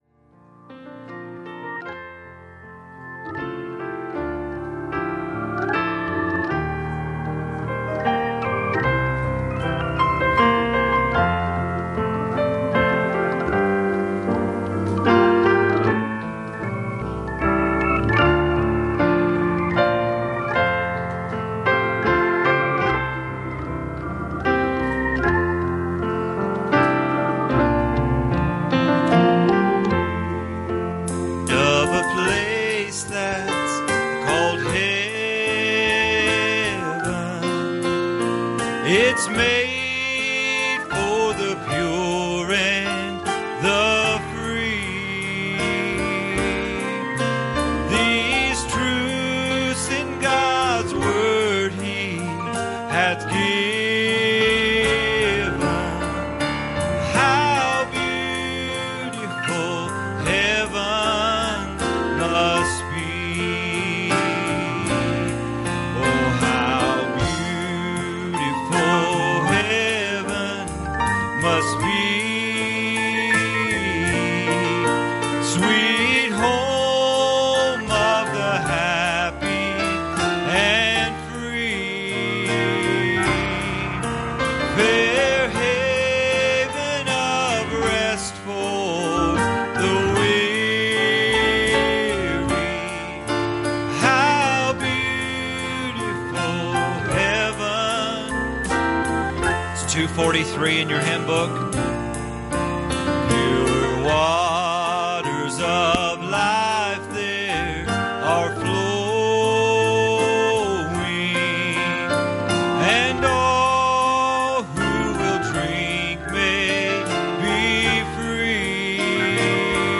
Passage: Revelation 19:6-8 Service Type: Wednesday Evening